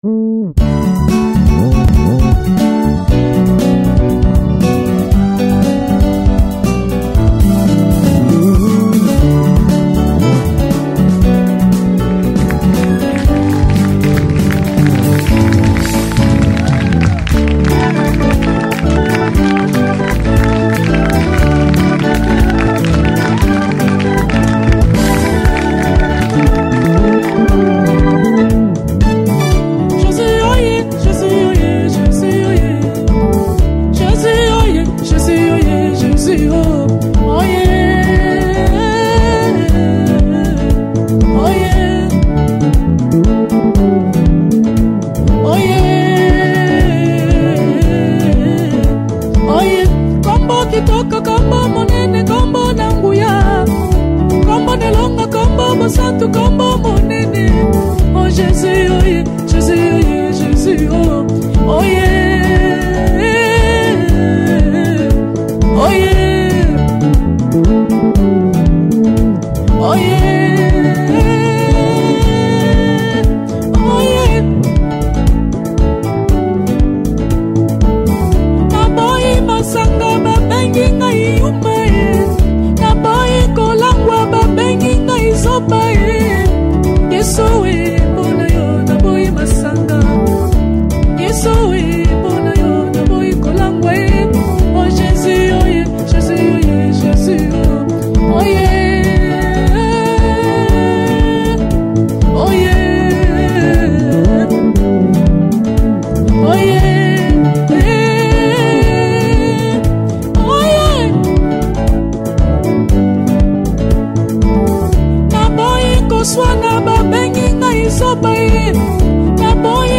Gospel 2017